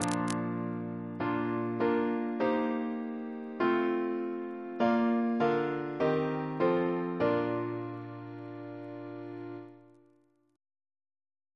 Single chant in B♭ Composer: Peter Hurford (1930-2019) Reference psalters: CWP: 202